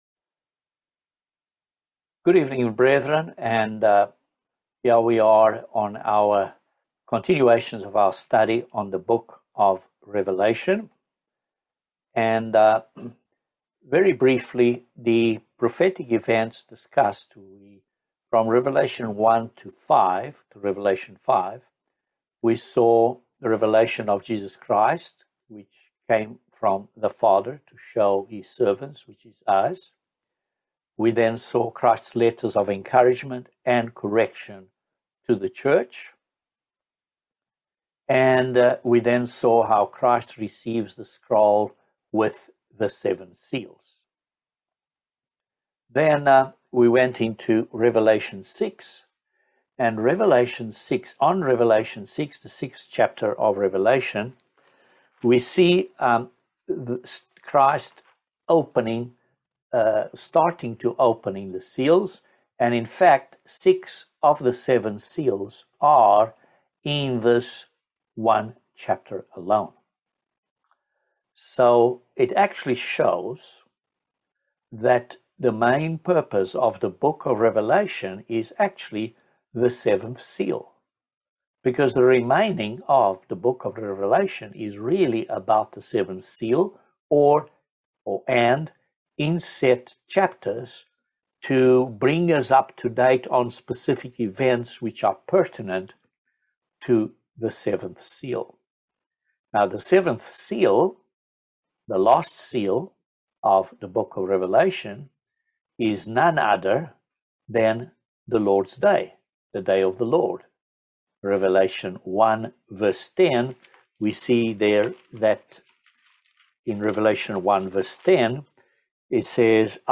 Bible Study No 15 of Revelation